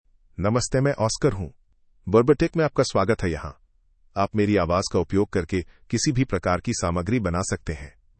Oscar — Male Hindi (India) AI Voice | TTS, Voice Cloning & Video | Verbatik AI
Oscar is a male AI voice for Hindi (India).
Voice sample
Listen to Oscar's male Hindi voice.
Oscar delivers clear pronunciation with authentic India Hindi intonation, making your content sound professionally produced.